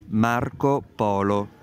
Marco Polo (/ˈmɑːrk ˈpl/ ; Venetian: [ˈmaɾko ˈpolo]; Italian: [ˈmarko ˈpɔːlo]